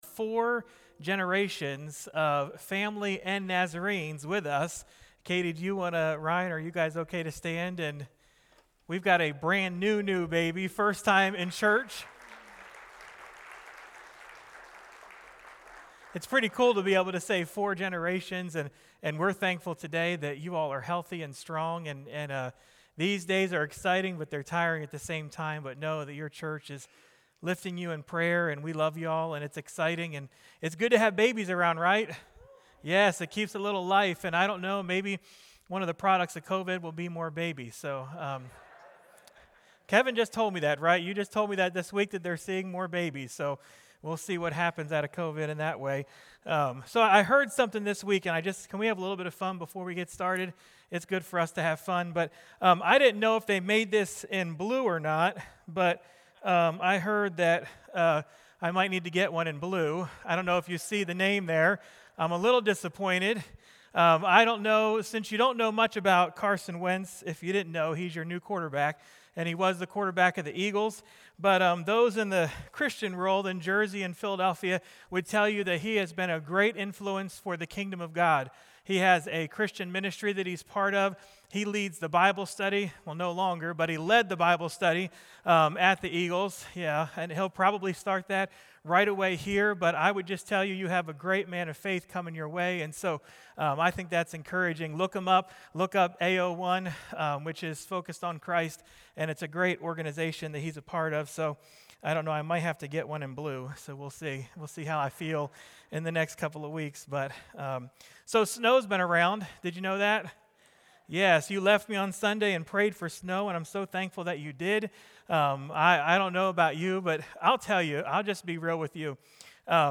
sermon-2-21-21.mp3